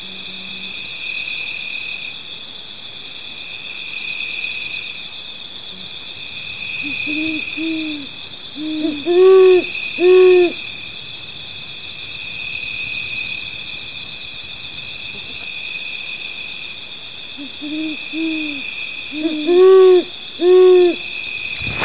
Great Horned Owl (Bubo virginianus)
dos ejemplares
Condition: Wild
Certainty: Photographed, Recorded vocal